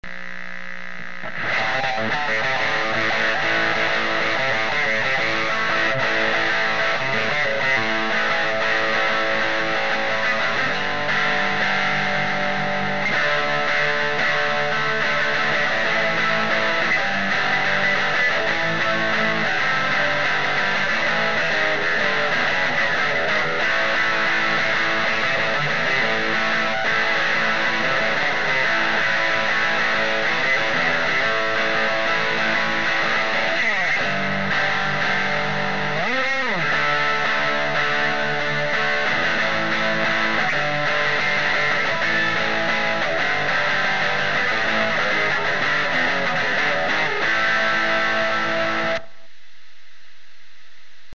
No effects were used either in the recording or mix down.